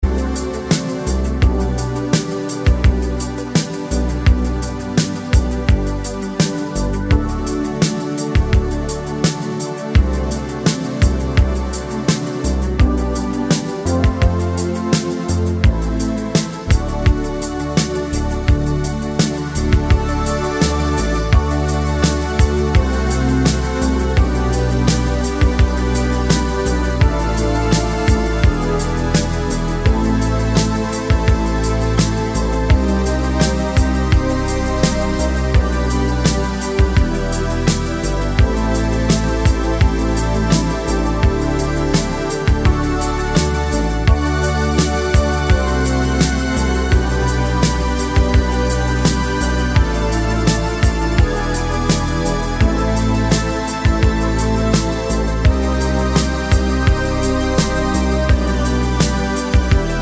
no Backing Vocals Soundtracks 4:27 Buy £1.50